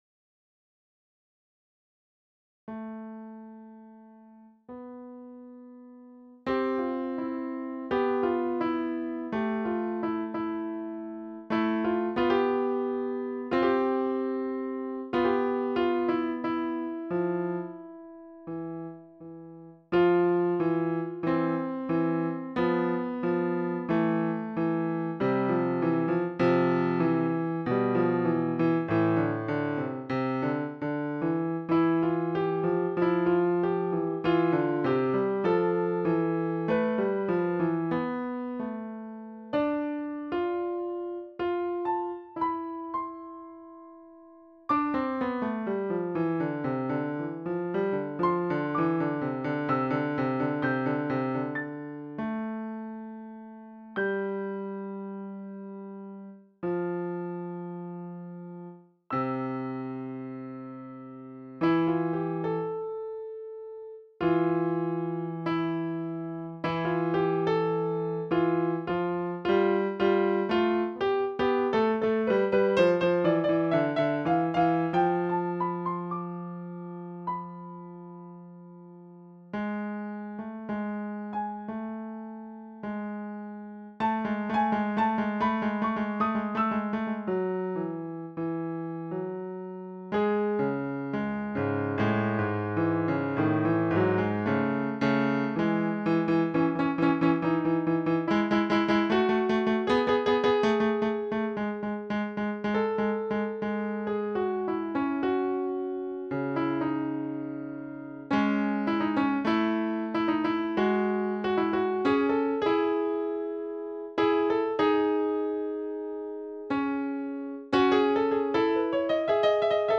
Posted in Classical, Piano Pieces Comments Off on